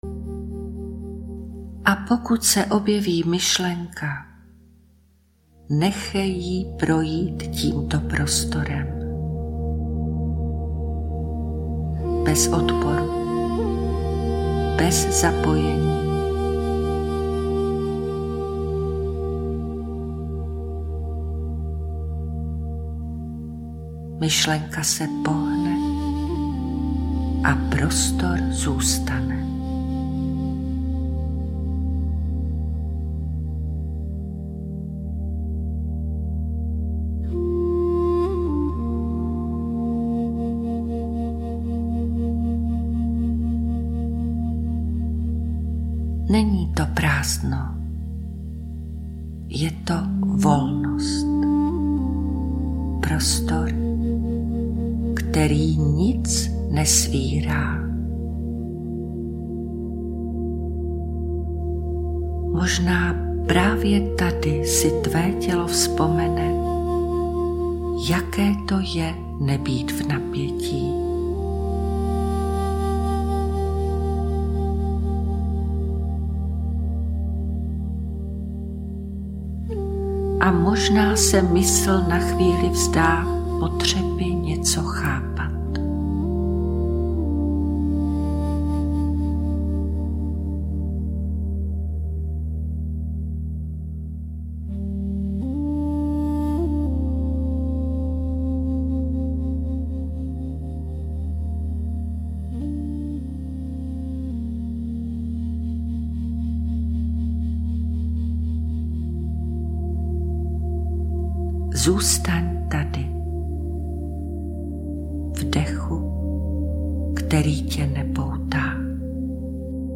obsahuje čtyři vedené meditace, které na sebe jemně navazují, ale můžeš je používat i samostatně podle aktuální potřeby.
V náruči dechudechová meditace pro zklidnění mysli a nervového systému